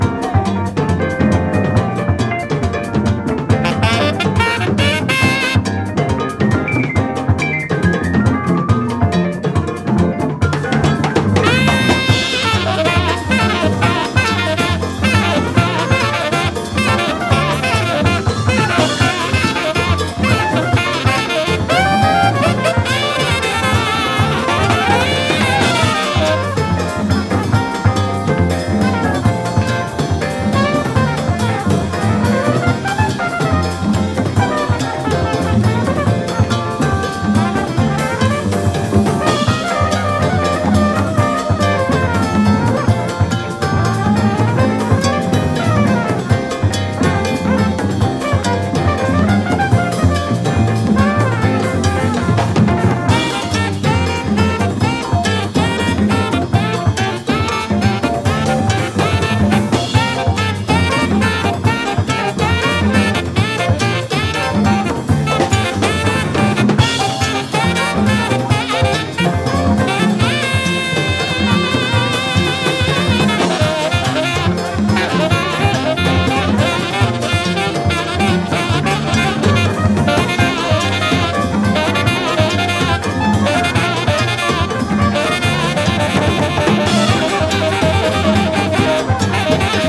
レアなジャズ・ファンク/レアグルーヴ盛り沢山の超即戦力コンピレーション！